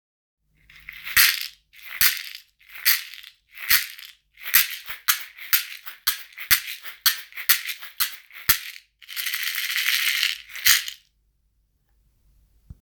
ストローがらがら 中
水草を編み上げたカラフルなカゴの中に、響きのよい小石や種が入っています。丸くカットしたひょうたんが底部分に組み込まれ、ジャカジャカ?♪と優しく心地よい音、自然の音が和みます。
素材： 水草 ヒョウタン 種 小石